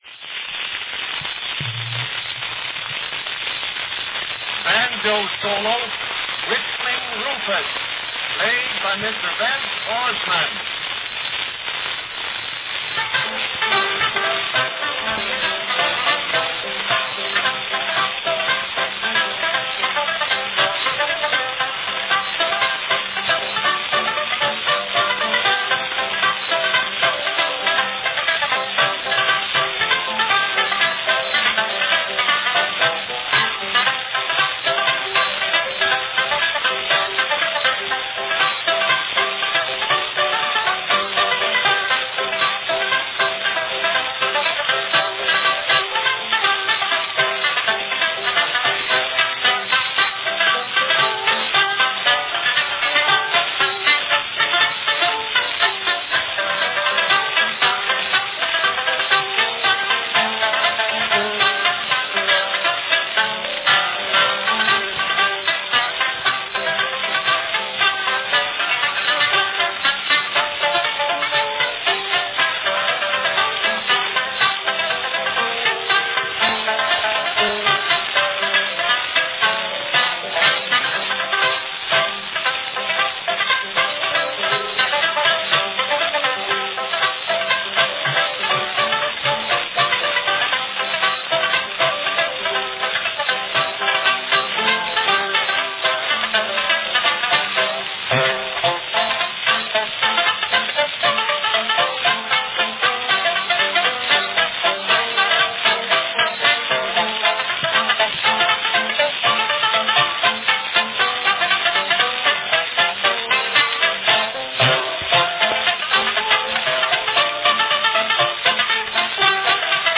Category Banjo solo